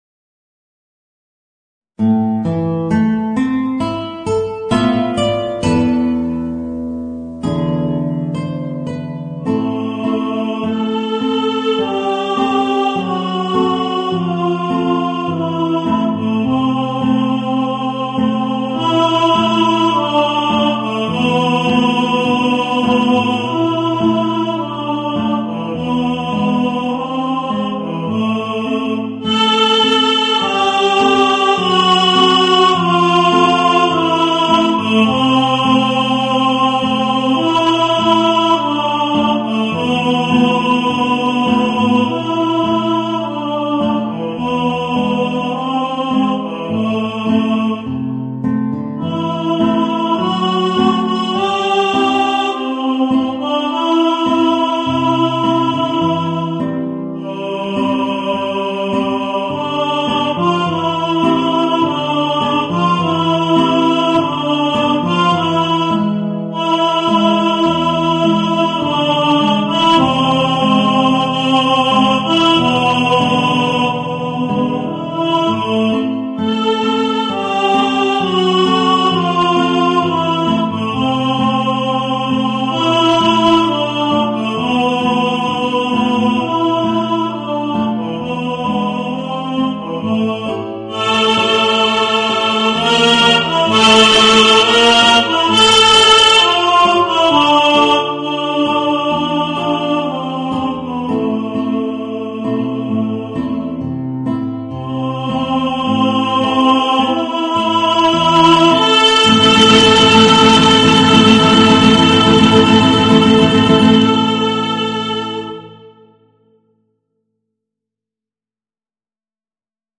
Voicing: Guitar and Tenor